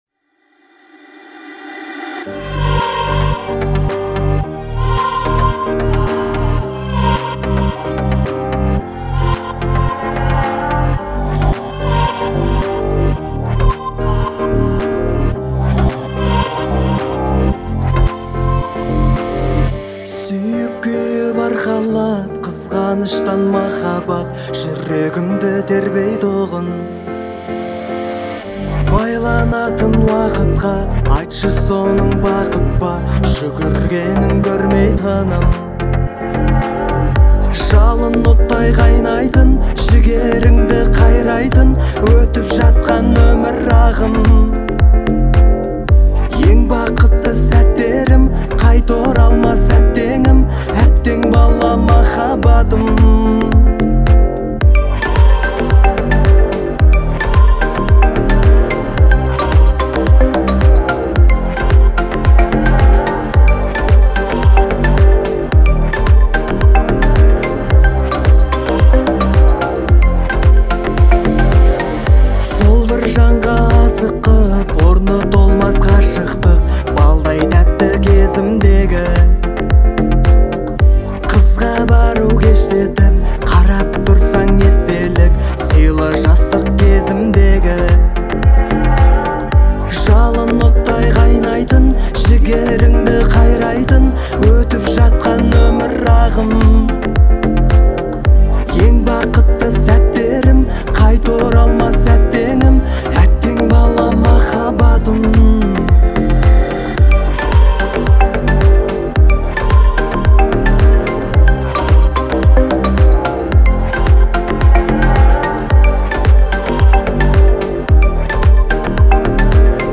это проникновенная песня в жанре казахской поп-музыки